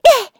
Taily-Vox_Landing.wav